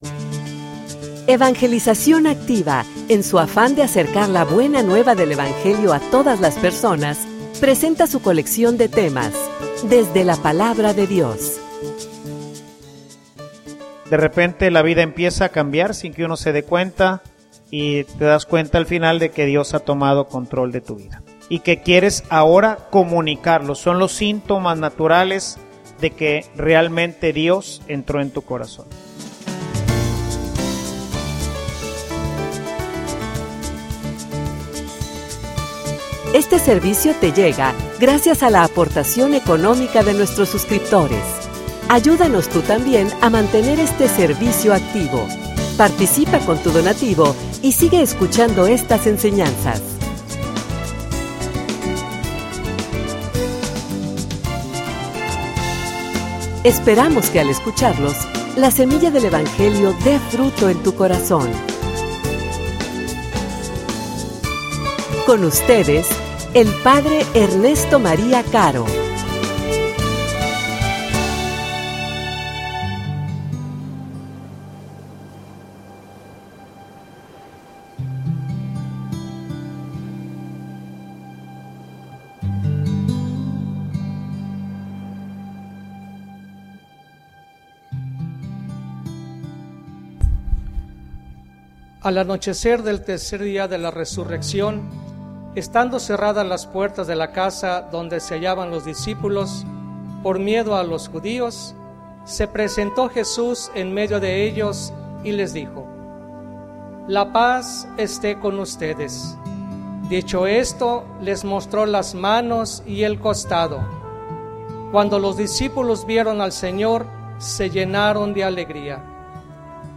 homilia_Seran_mis_testigos.mp3